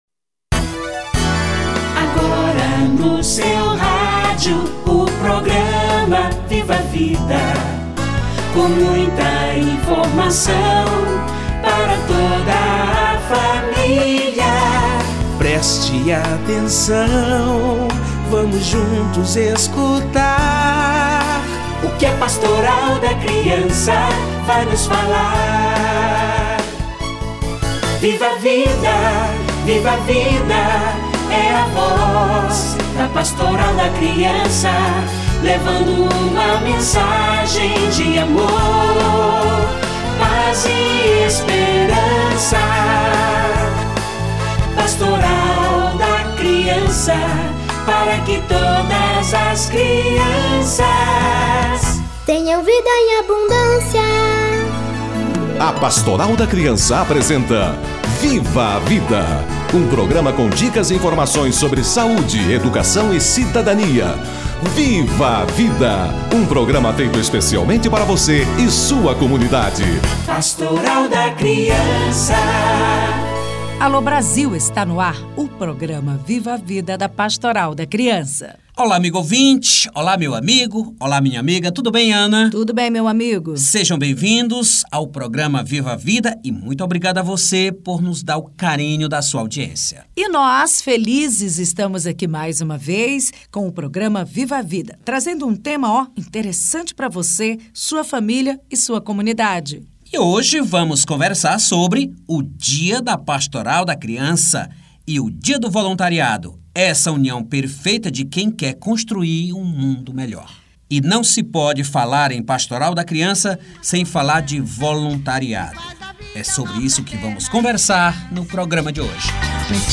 Dia da Pastoral da Criança / Dia Internacional do Voluntário - Entrevista